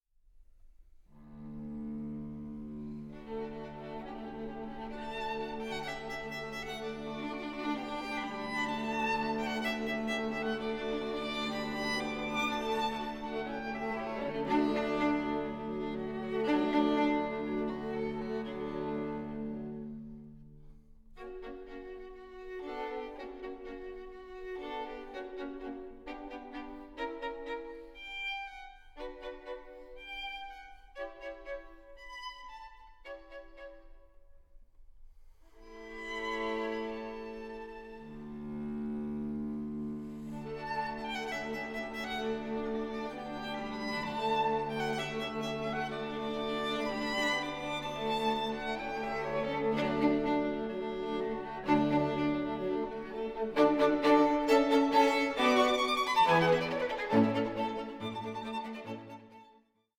for string quartet